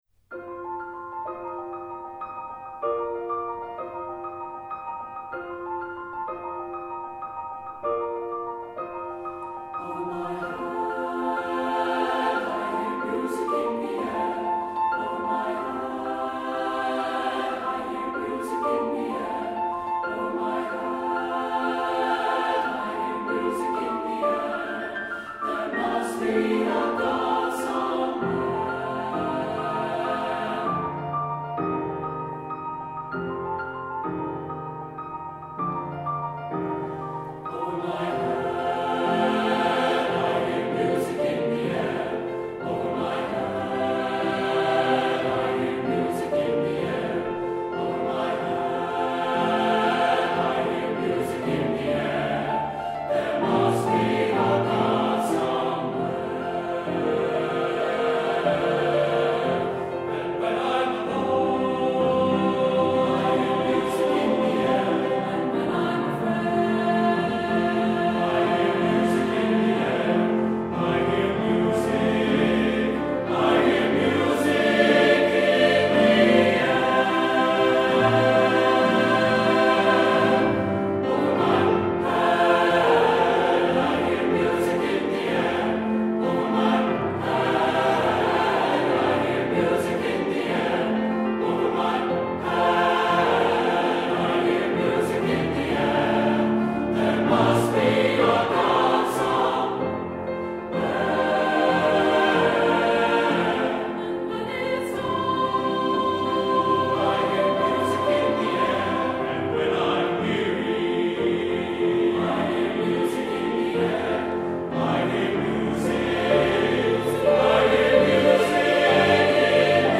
Composer: African-American Spiritual
Voicing: SATB and Piano